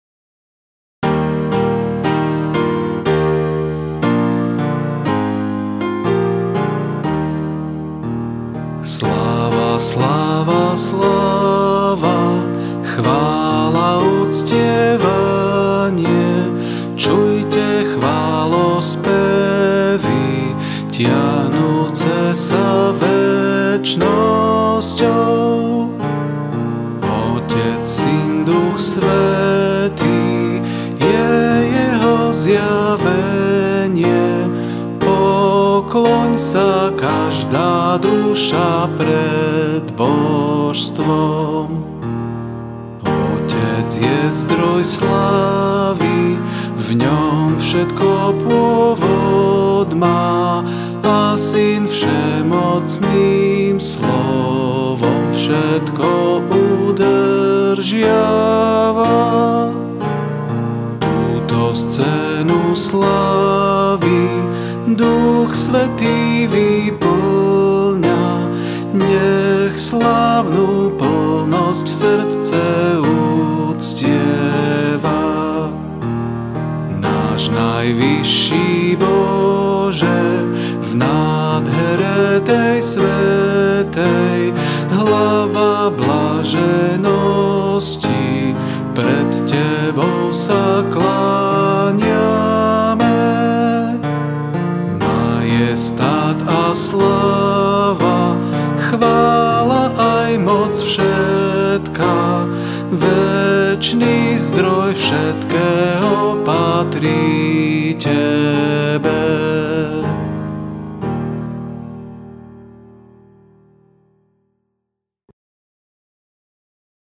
F大調